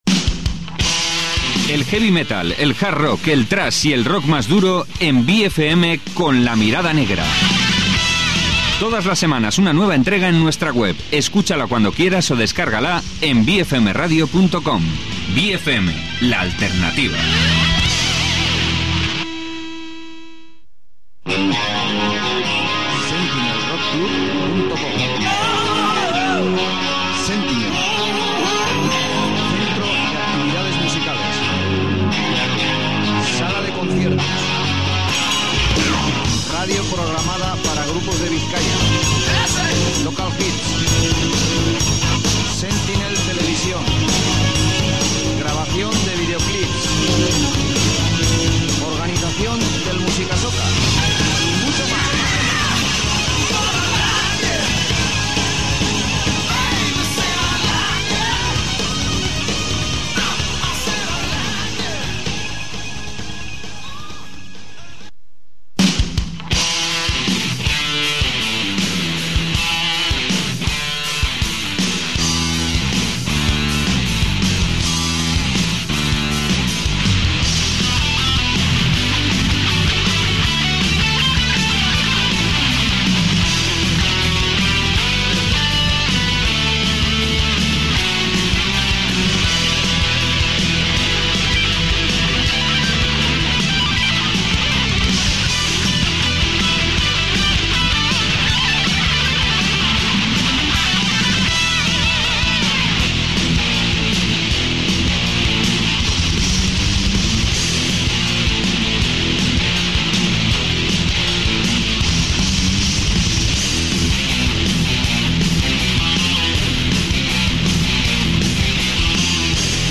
Entrevista con Viento Norte